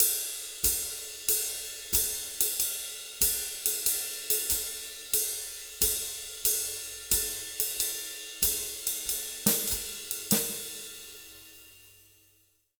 92SWING 04-L.wav